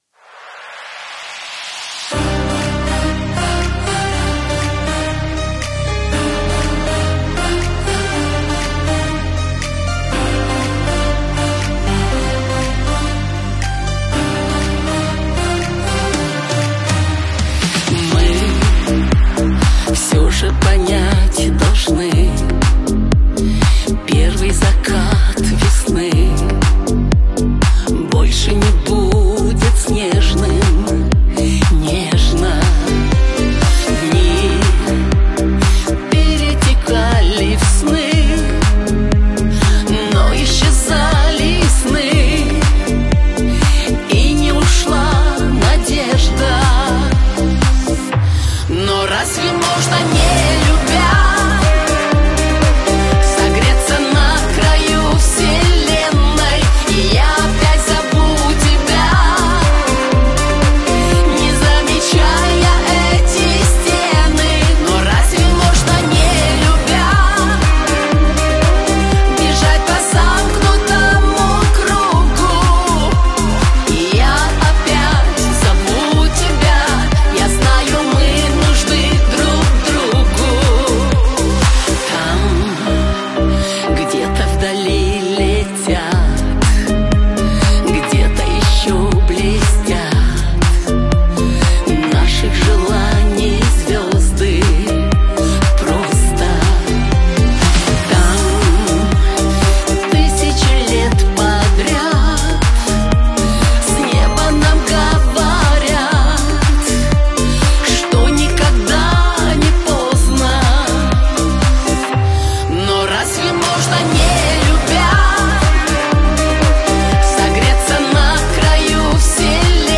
это яркая и мелодичная песня в жанре поп
Особенностью исполнения является мощный вокал